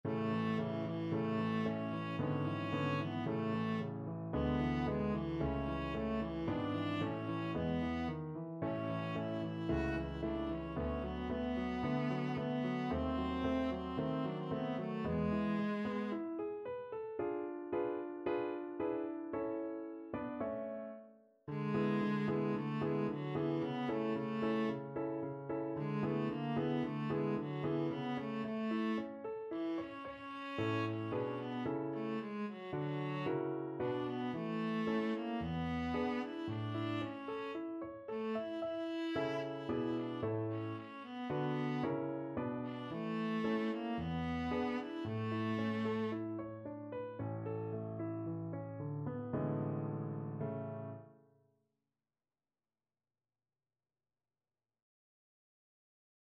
Viola version
2/4 (View more 2/4 Music)
~ = 56 Affettuoso
E4-F#5
Classical (View more Classical Viola Music)